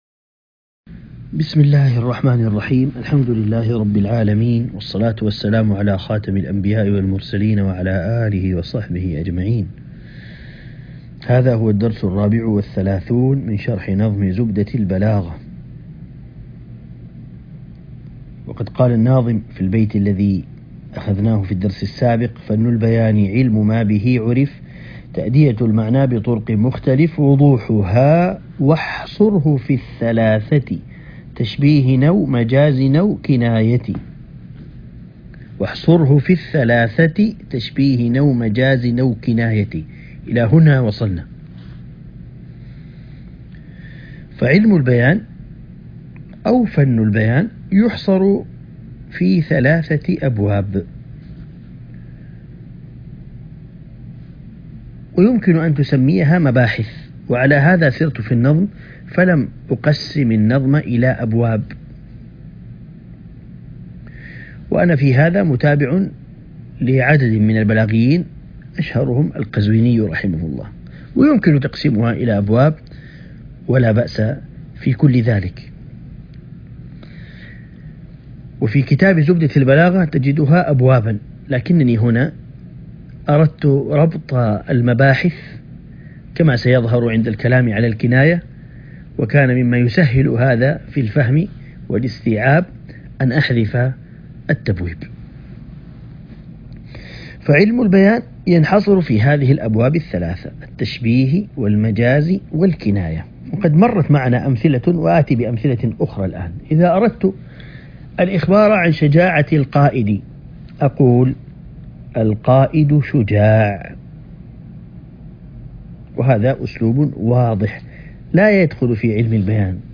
عنوان المادة الدرس ( 34) شرح نظم زبدة البلاغة